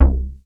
DEEP C3.wav